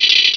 Cri de Polichombr dans Pokémon Rubis et Saphir.